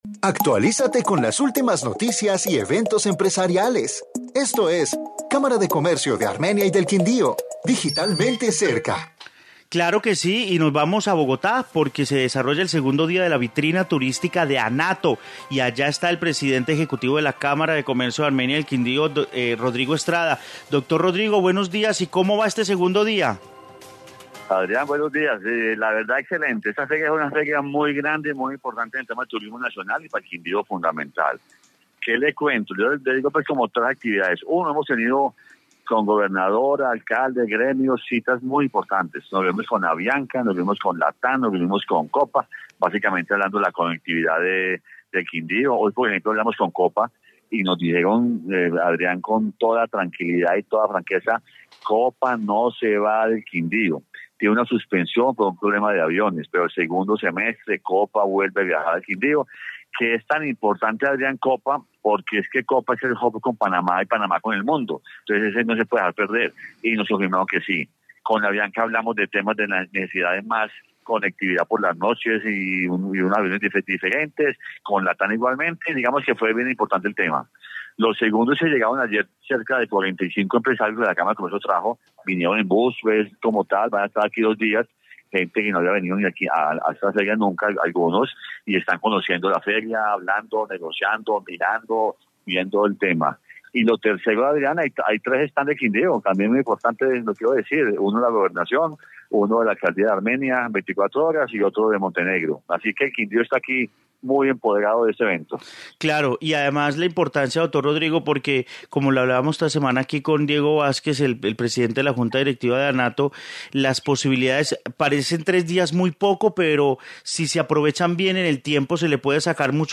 Informe Anato